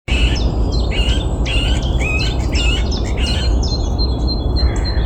Putns (nenoteikts), Aves sp.
Administratīvā teritorijaRīga
StatussDzirdēta balss, saucieni
PiezīmesVairākkārtīgi bļāva no dažādiem kokiem, tā arī nesanāca vizuāli noverot.